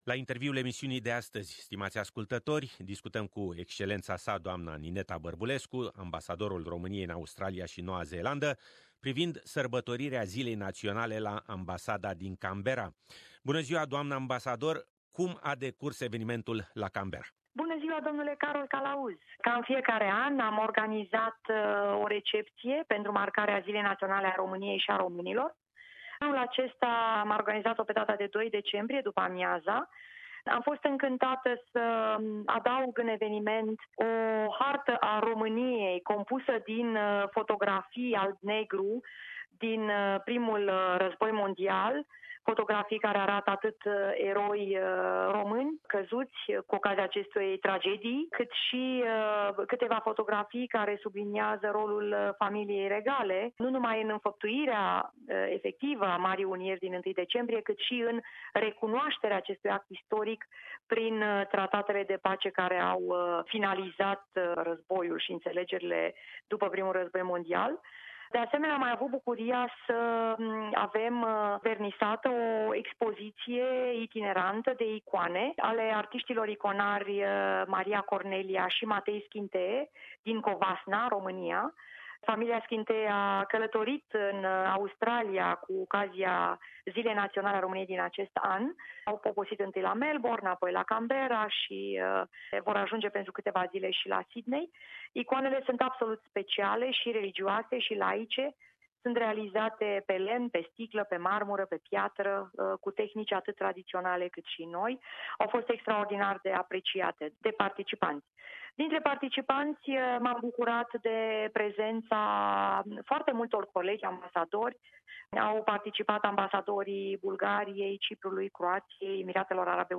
Ziua Nationala si Festival cultural la Canberra: Interviu cu Ambasadorul Romaniei in Australia si Noua Zeelanda, Nineta Barbulescu - interviu intreg